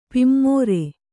♪ pimmōre